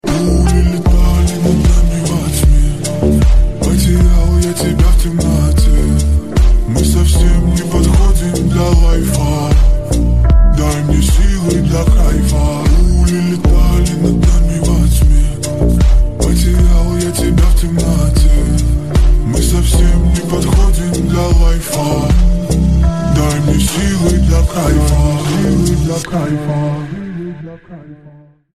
Спокойные И Тихие Рингтоны
Рингтоны Ремиксы » # Поп Рингтоны